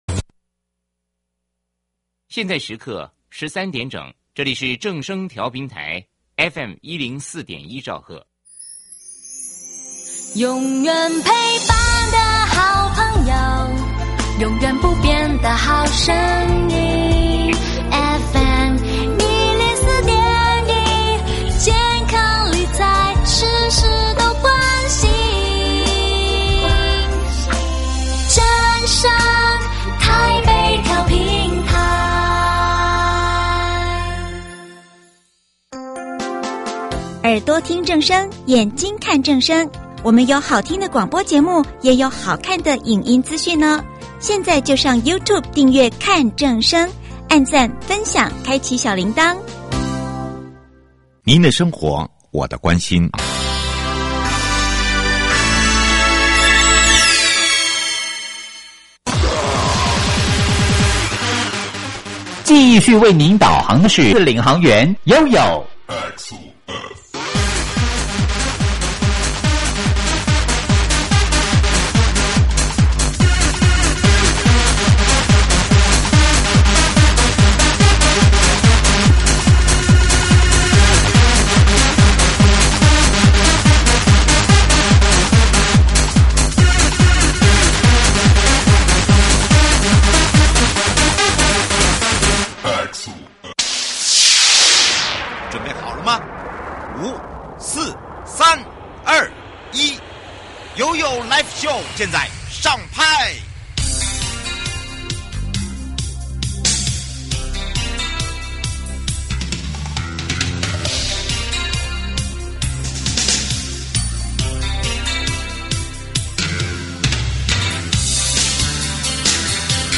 受訪者： 營建你我他 快樂平安行~七嘴八舌講清楚~樂活街道自在同行!(四) 「安全、友善、永續」雲林道路大改造
節目內容： 1.國土署都市基礎工程組 2.雲林縣交通工務局汪令堯局長(四)